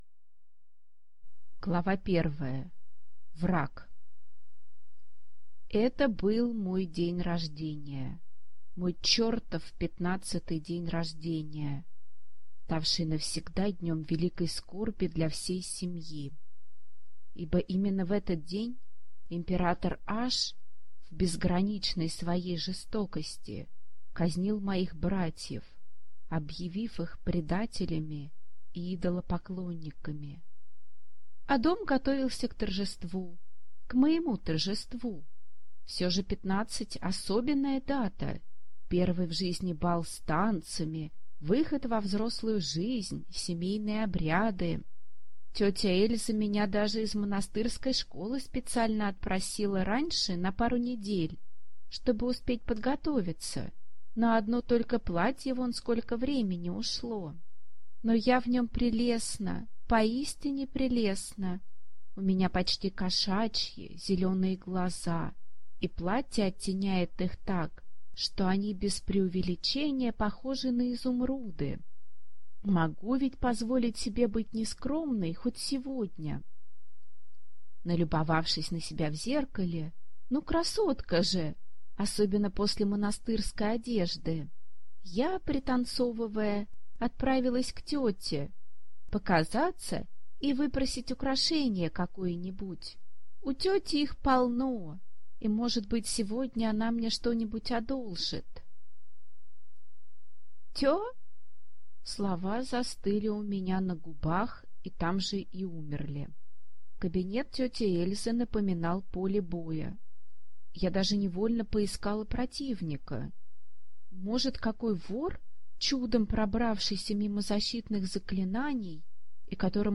Аудиокнига Месть Аники дес Аблес | Библиотека аудиокниг